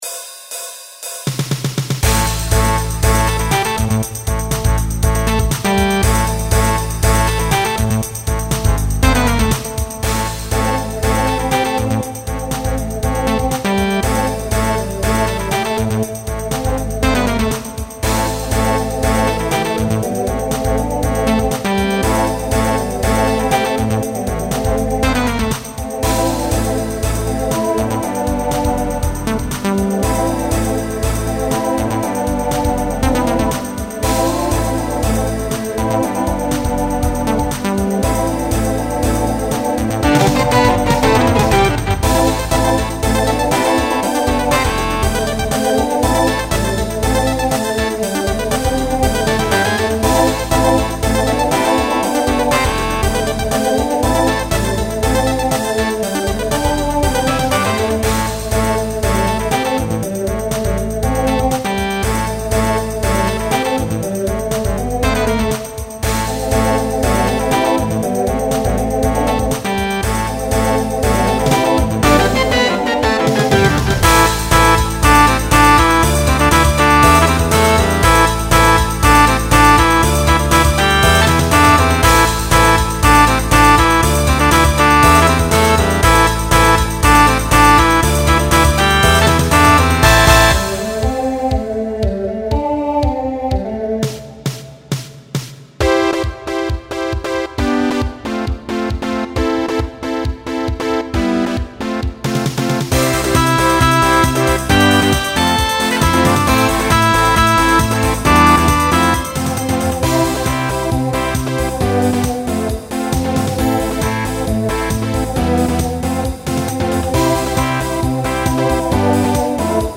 Genre Pop/Dance Instrumental combo
Voicing Mixed